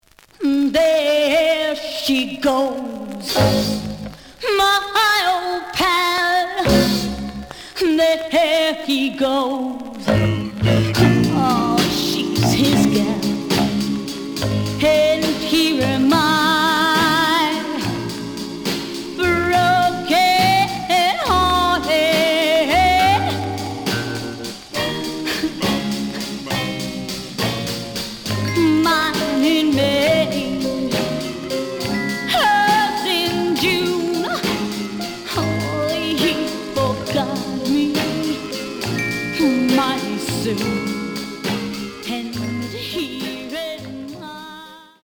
試聴は実際のレコードから録音しています。
●Genre: Rhythm And Blues / Rock 'n' Roll
●Record Grading: G+ (両面のラベルにダメージ。盤に若干の歪み。傷は多いが、プレイはまずまず。)
G+, G → 非常に悪い。ノイズが多い。